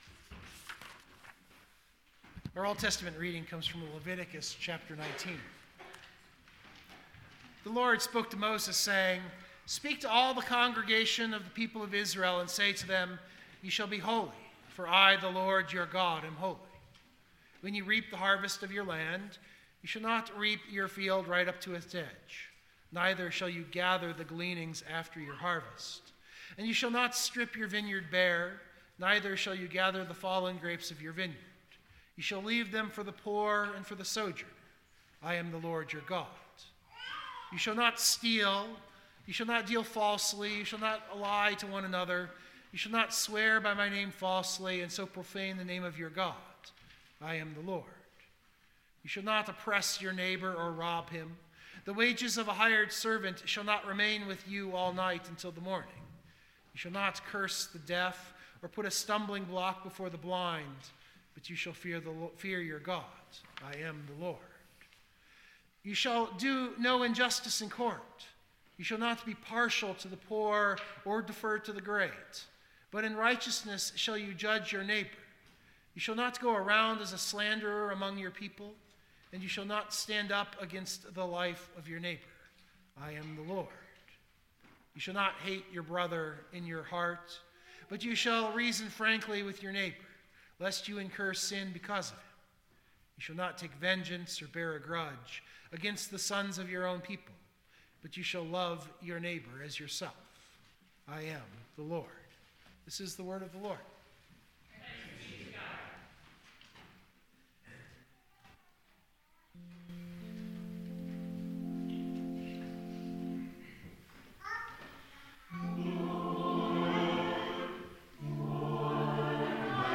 This sermon preaches salt and light as Luther would have it. The salt being the stinging but preserving function of the law while the light is the proclamation of the gospel of free grace.